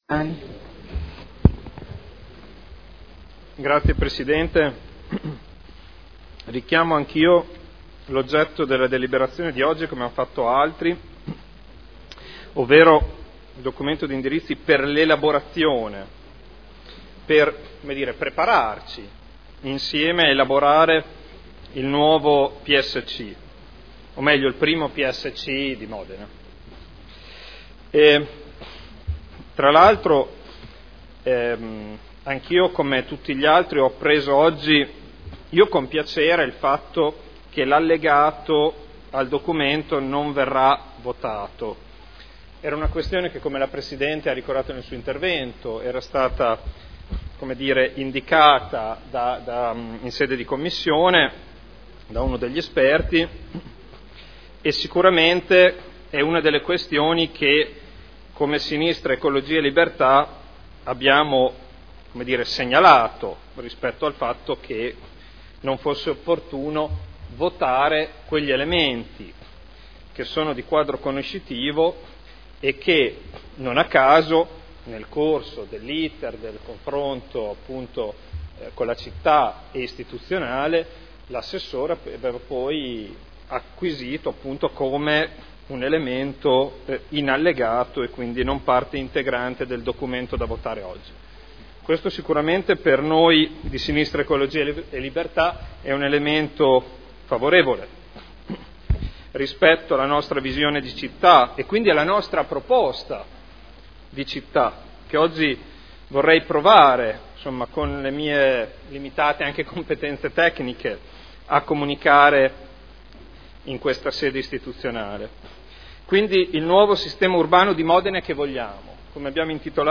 Federico Ricci — Sito Audio Consiglio Comunale
Seduta del 18/03/2012 Dibattito. Documento di indirizzi per l’elaborazione del nuovo Piano Strutturale Comunale (PSC)